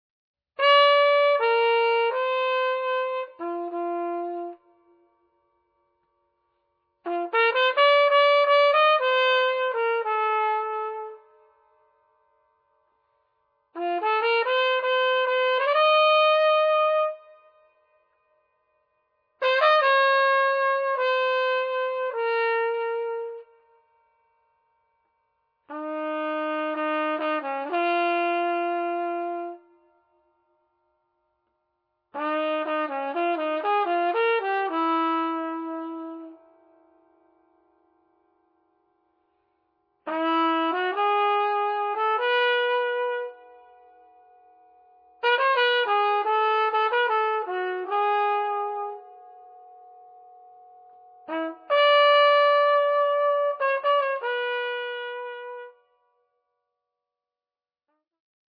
Jazz
free jazz to the core